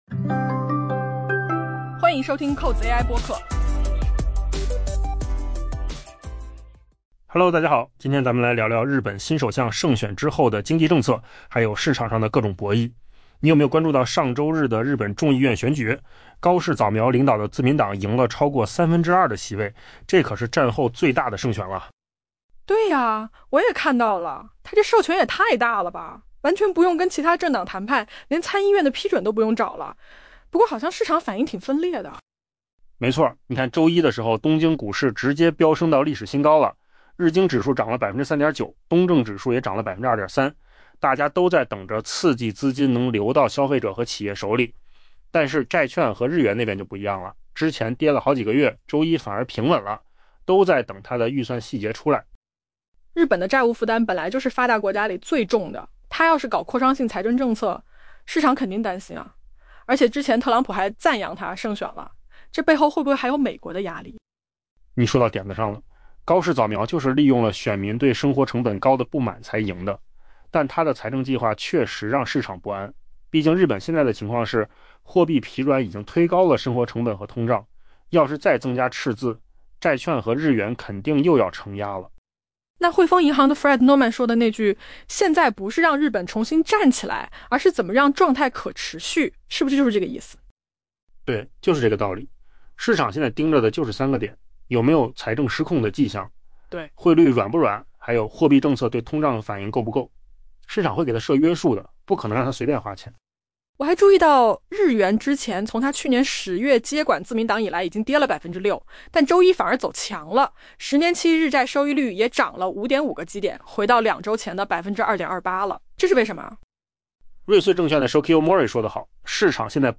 AI 播客：换个方式听新闻 下载 mp3 音频由扣子空间生成 尽管日本战后历史上最大的胜选令日本首相高市早苗拥有巨大的授权来重振经济，但投资者表示， 她几乎没有增加赤字的空间，否则压力将很快回到债券和日元身上。